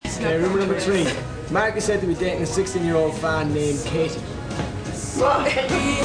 Parts of the interview (the blue ones) are also recorded in MP3 format!!!